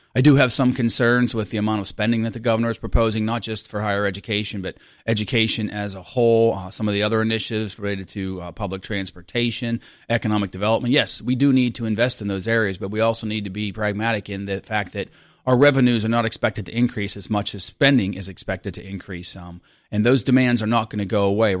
State Representative Jim Struzzi said one of his concerns was how much money Governor Shapiro wants to spend.